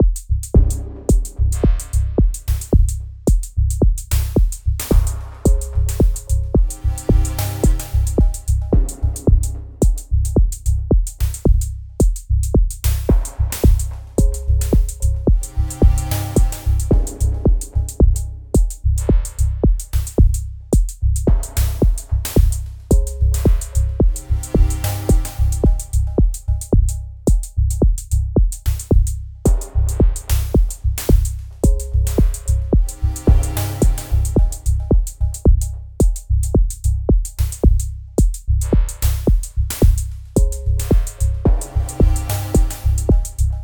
4 Dub techno with the FM Synth🔗
Here’s audio of the groove repeated four times, with just an abrupt beginning and ending. The repetitions are not identical due to the use of FX commands on some tracks, which will be explained in due course.
We’ve slowed the tempo to 110 bpm (in Project View), which is slower than most techno but faster than most dub.
The first four tracks contain percussive elements: a kick drum, a snare, a hi-hat, and a bell.